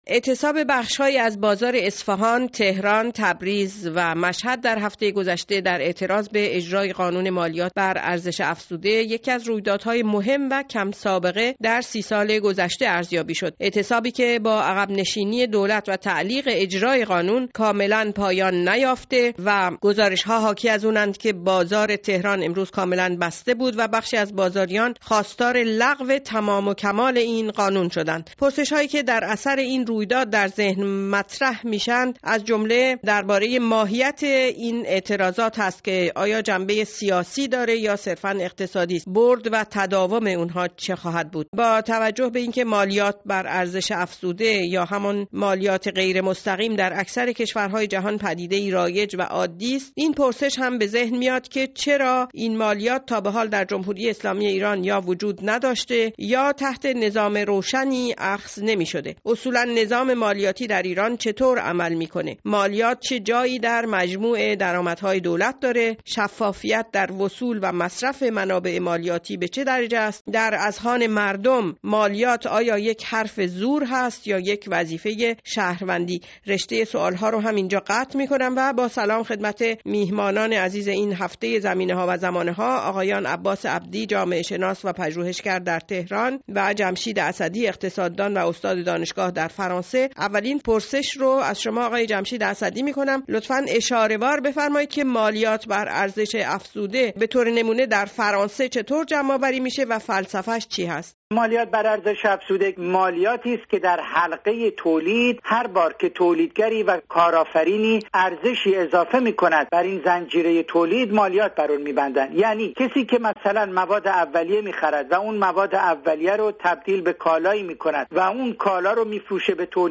این میز گرد را گوش کنید